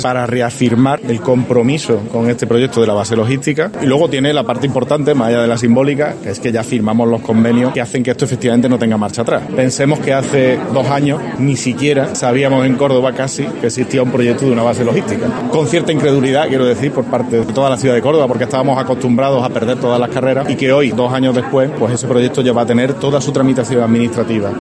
En declaraciones a los periodistas, el regidor ha aseverado que la firma del convenio es "un paso importantísimo", destacando que tiene "dos notas fundamentales: la puramente simbólica, de volver a reunirse todas las administraciones con sus máximos representantes" para "reafirmar el compromiso con este proyecto de la base logística", que se va "por el buen camino" y que "es un proyecto imparable", ha subrayado.